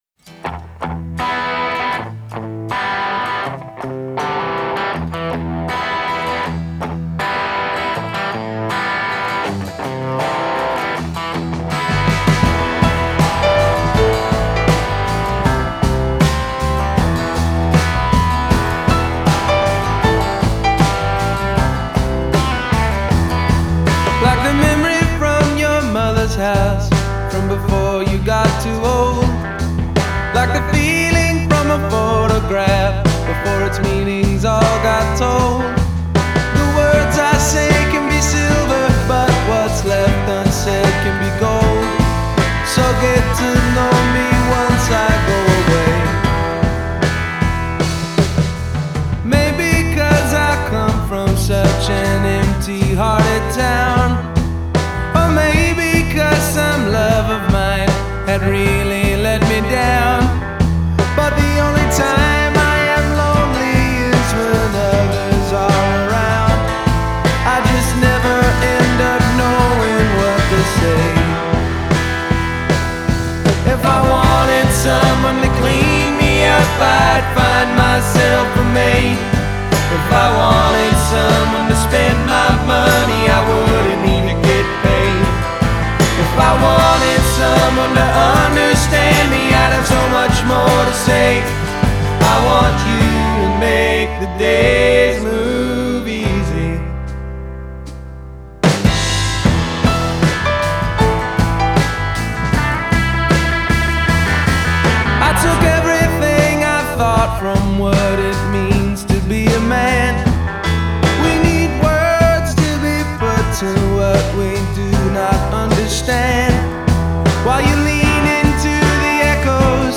definitely alt-country/folk sound